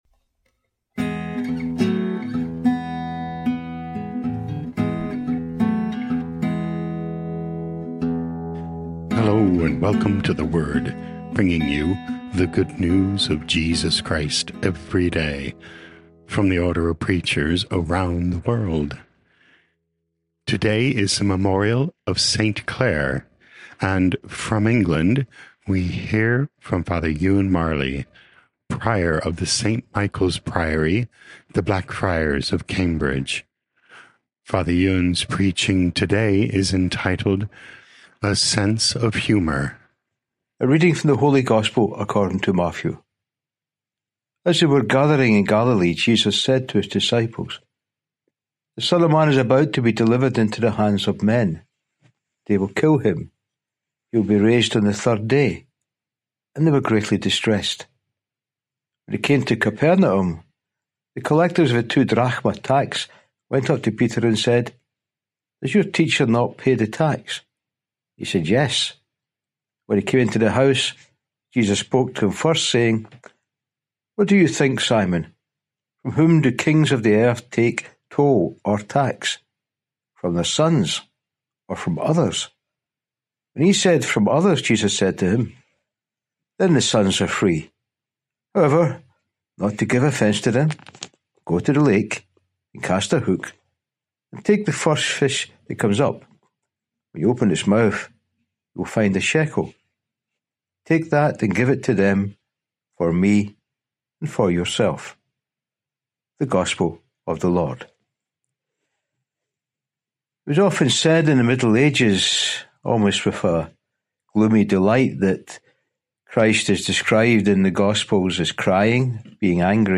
O.P. Preaching